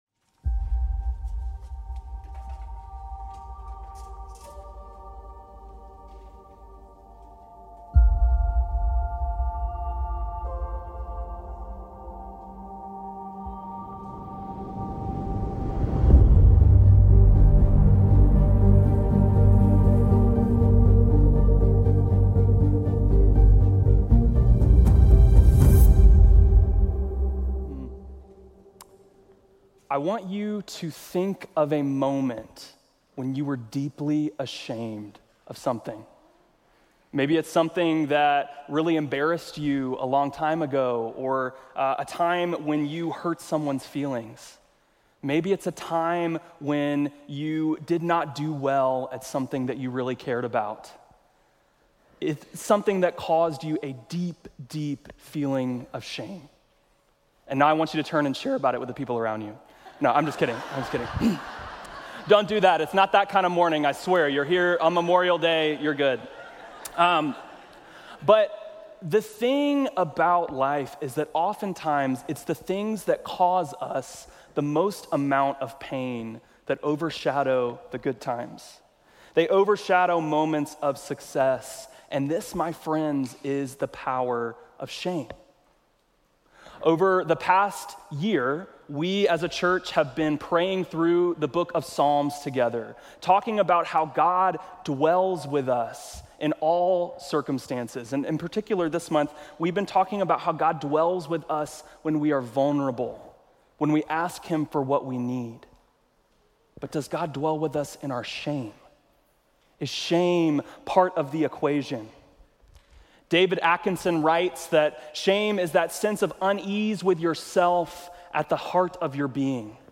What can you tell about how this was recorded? Listen weekly to relevant and engaging messages from Peachtree's teaching team in its traditional worship venue, the Sanctuary.